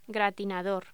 Locución: Gratinador
voz